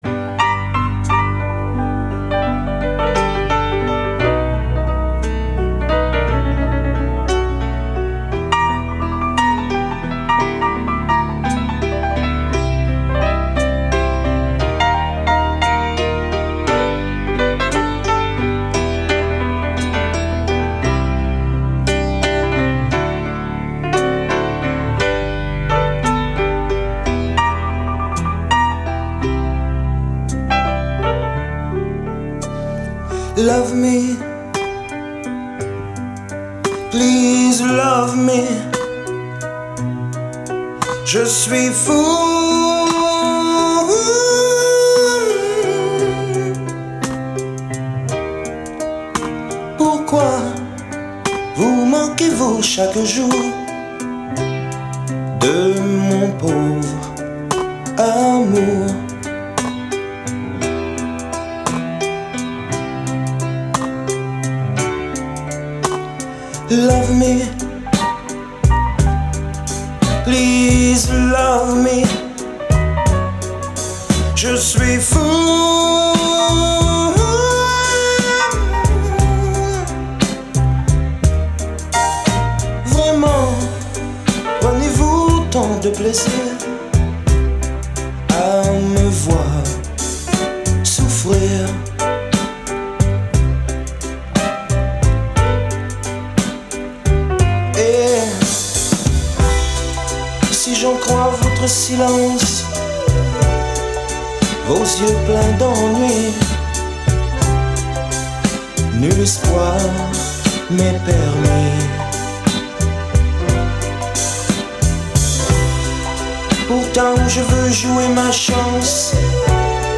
Класс. Блюз - это настроение и заряд.